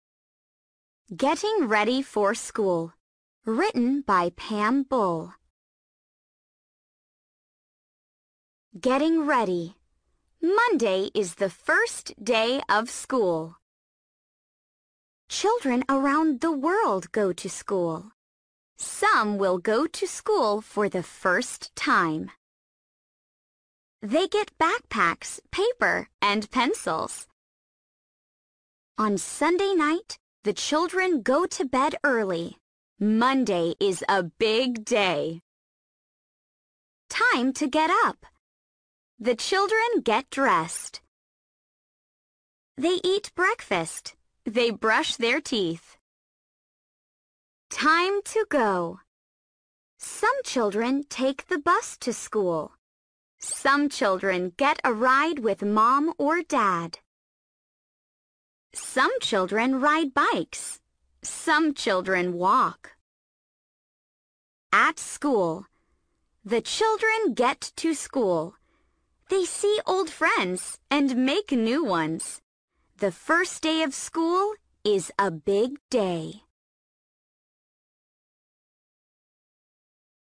听力练习：